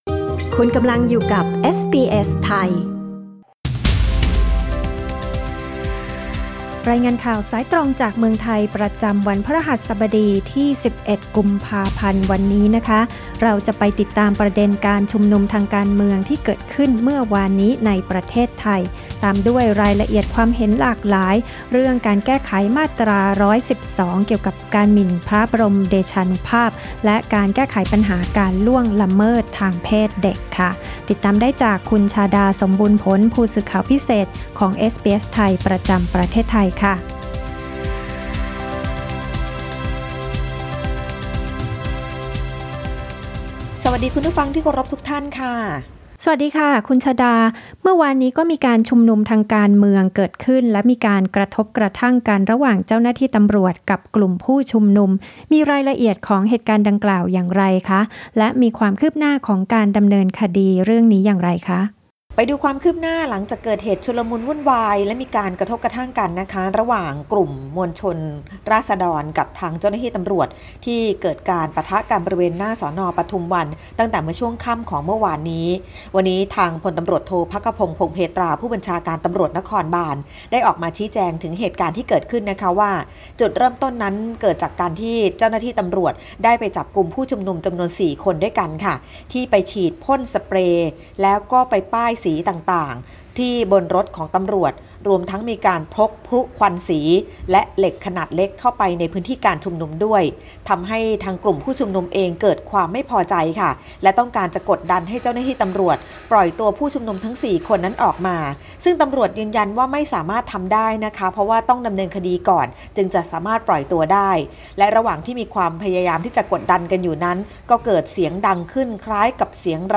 รายงานสายตรงจากเมืองไทย โดยเอสบีเอส ไทย Source: Pixabay